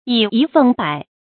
以一奉百 注音： ㄧˇ ㄧ ㄈㄥˋ ㄅㄞˇ 讀音讀法： 意思解釋： 奉：供養。